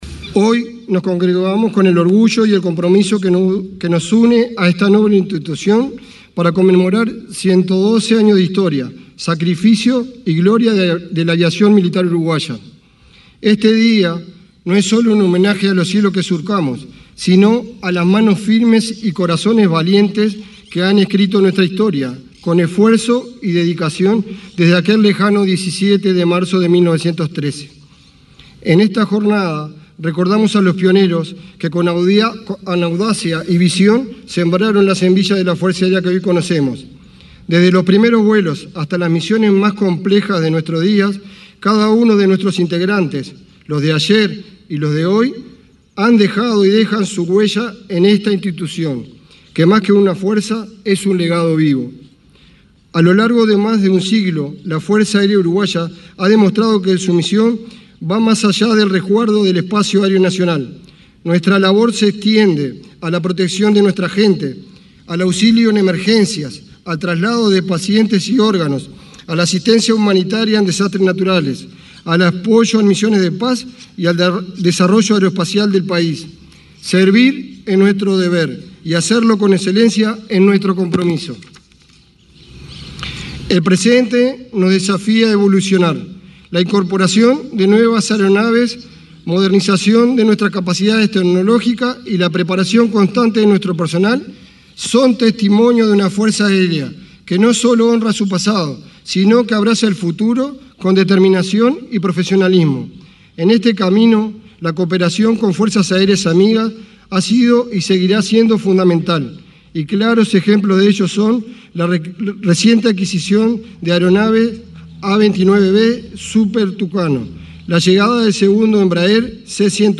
Oratoria del comandante en jefe de la Fuerza Aérea, Fernando Colina
Oratoria del comandante en jefe de la Fuerza Aérea, Fernando Colina 17/03/2025 Compartir Facebook X Copiar enlace WhatsApp LinkedIn El comandante en jefe de la Fuerza Aérea Uruguaya, Fernando Colina, se expresó, durante el acto aniversario de esa dependencia. El presidente de la República, profesor Yamandú Orsi, encabezó la ceremonia, realizada este lunes 17 en Montevideo.